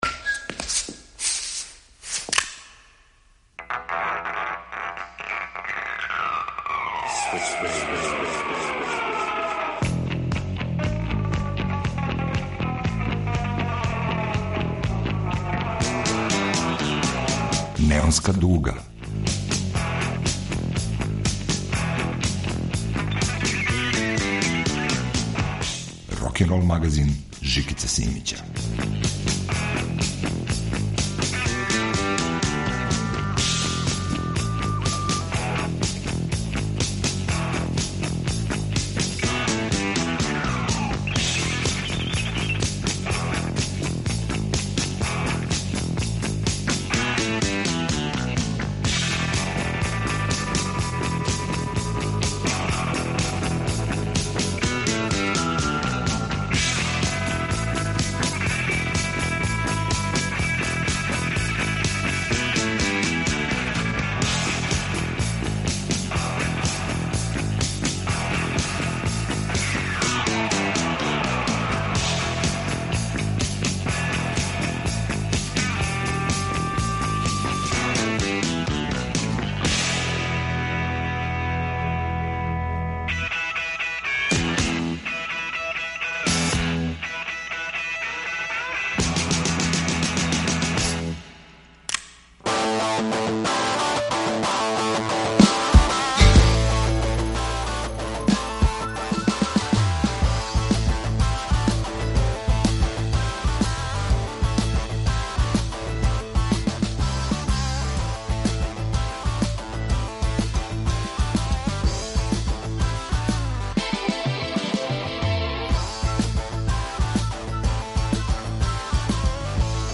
Neonska duga - rokenrol magazin